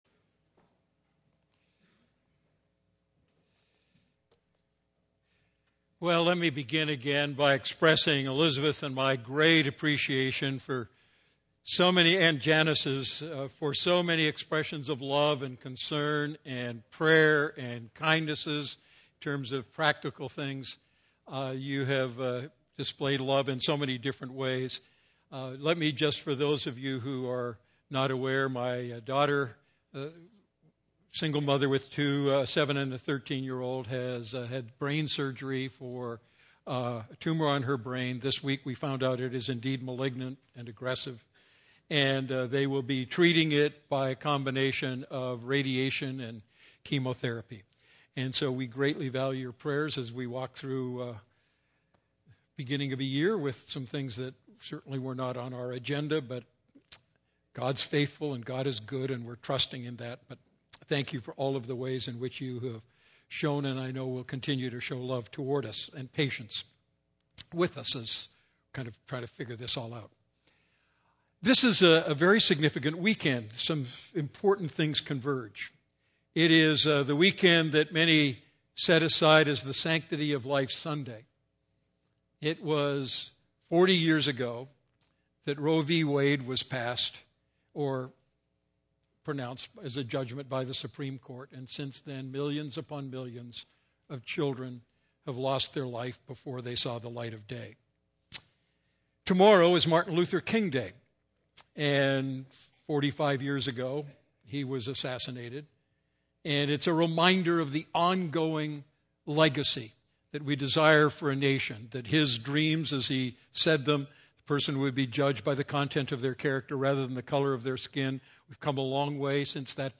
A message from the series "Great Questions?."